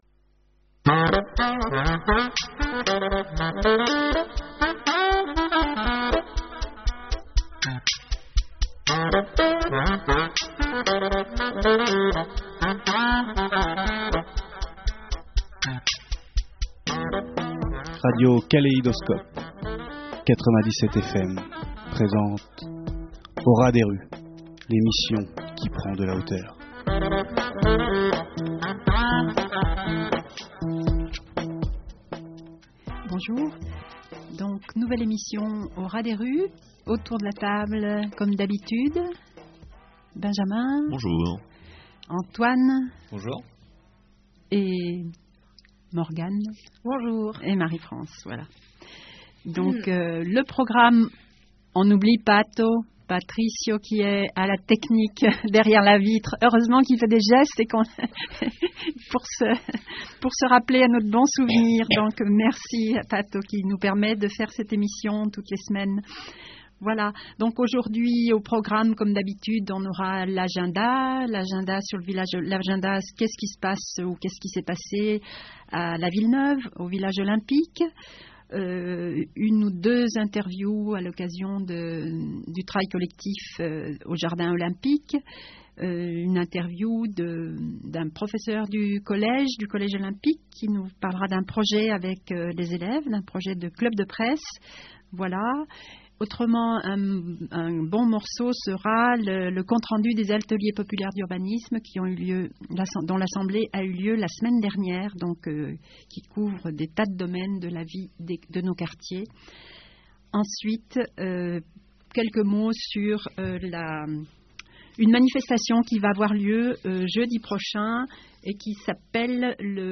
Au ras des rues est une nouvelle émission hebdomadaire sur Radio Kaléidoscope (97 fm) qui s’intéresse à l’actualité des quartiers sud de Grenoble et de l’agglo : Villeneuve, Village Olympique, Mistral, Abbaye-Jouhaux, Ville Neuve d’Échirolles…